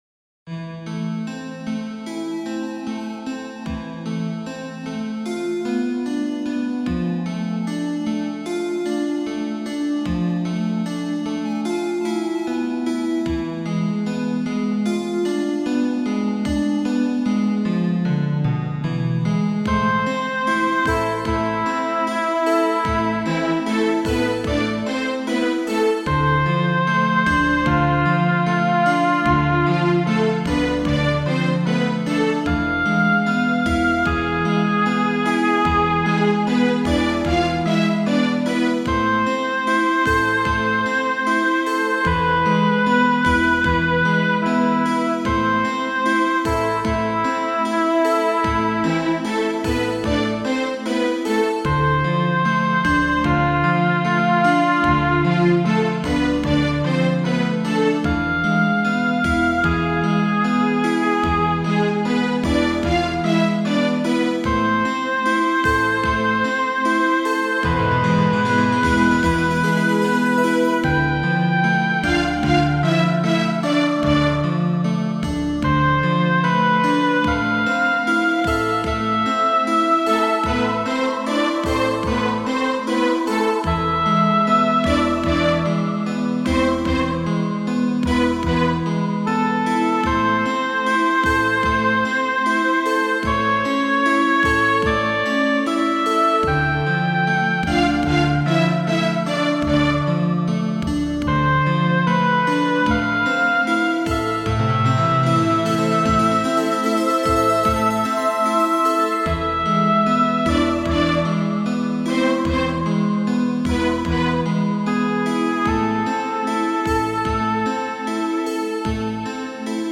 Spacesynth Trance Techno
Neoclassical Healing Music
Ambient